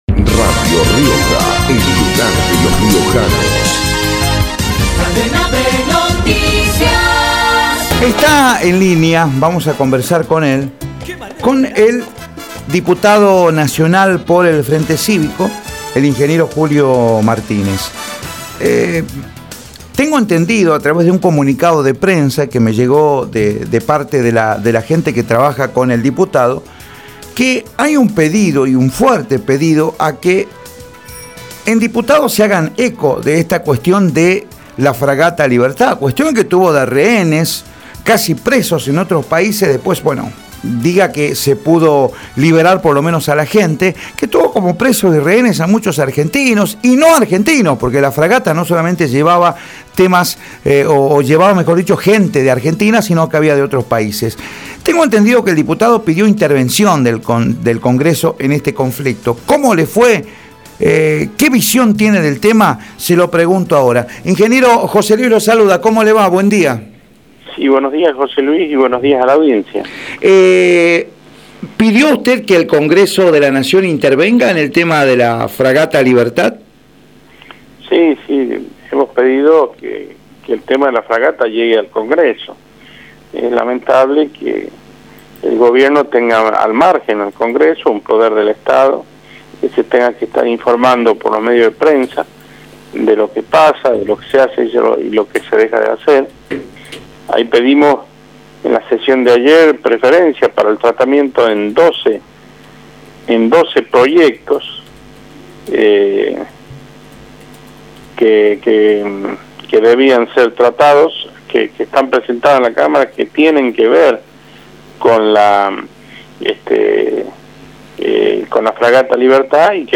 Julio Martínez, diputado nacional, por Radio Rioja
julio-martc3adnez-diputado-nacional-por-radio-rioja.mp3